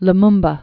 (l-mmbə), Patrice Emery 1925-1961.